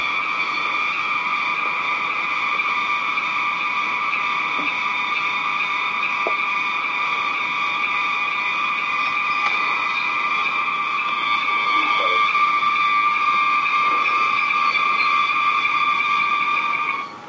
Brazil selva sounds » Mineiros Brasil selva 7.12.17 afternoon crickets stop
描述：Afternoon insects on a Brazilian farm in rainy season: cicadas and crickets(?).
标签： nature fieldrecording cicadas Brazil insects
声道立体声